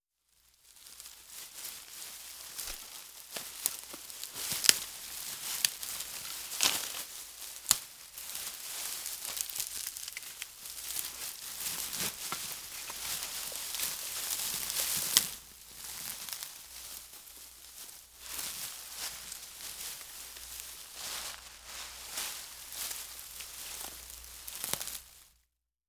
crunching leaves